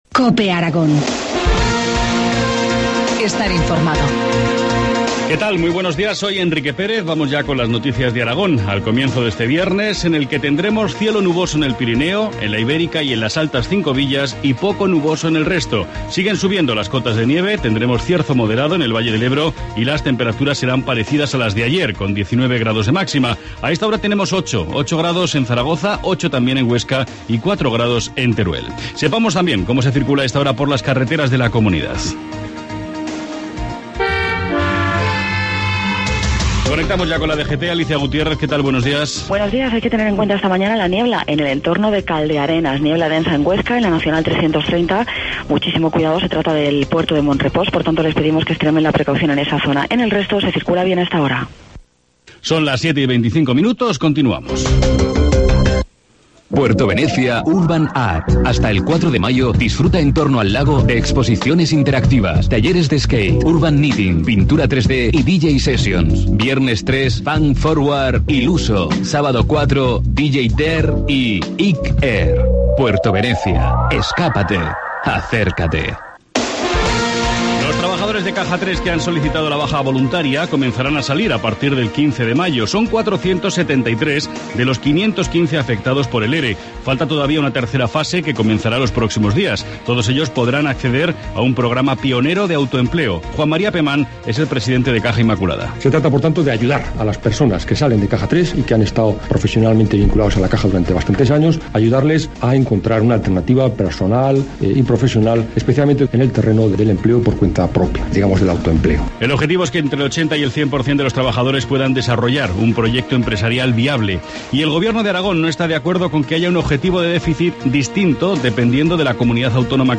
Informativo matinal, viernes 3 de mayo, 7.25 horas